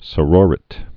(sə-rôrĭt)